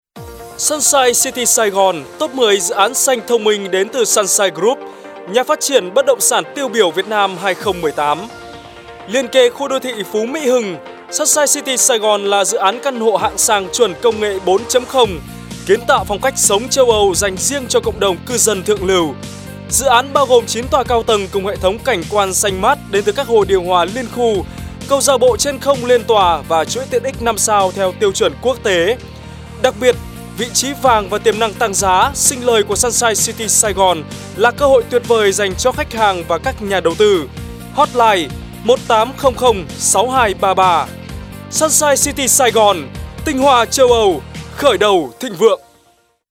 Mẫu giọng thu voice quảng cáo VOV
MẪU GIỌNG MC MIỀN BẮC
MC Miền Bắc